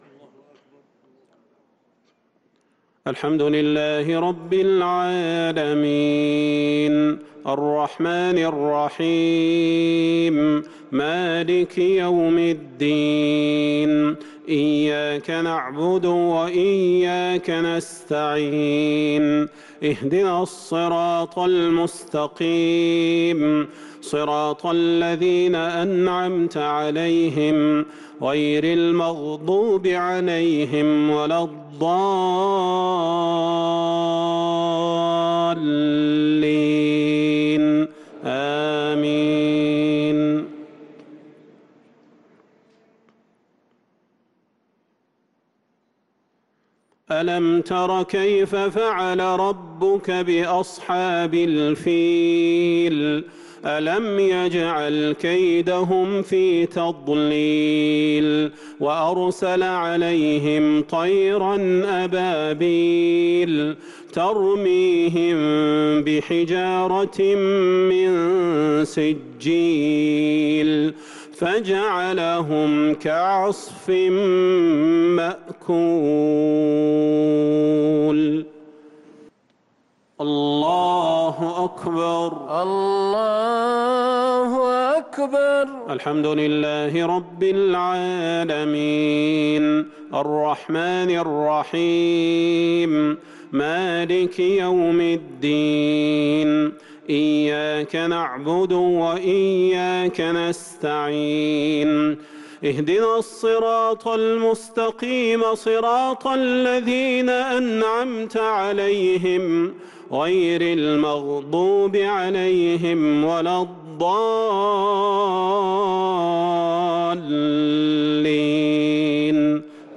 عشاء الأربعاء 5رمضان 1443هـ سورتي الفيل و قريش | Isha prayer surat al-Fil & Quraish 5-4-2022 > 1443 🕌 > الفروض - تلاوات الحرمين